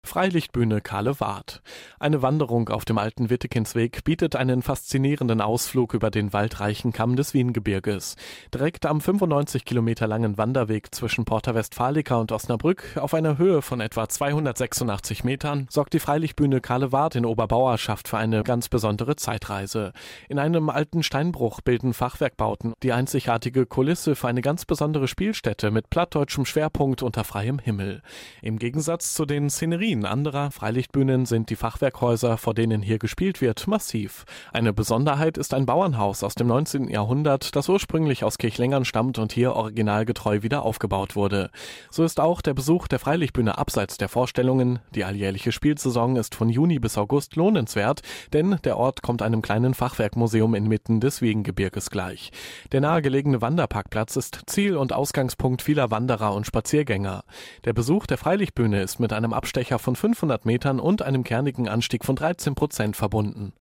Audioguide Bauernbad-Radelroute Bünde
Mit dem Audioguide zur Bauernbad-Themenroute in Bünde erhalten Sie gesprochene Informationen zu den Besonderheiten entlang der Route.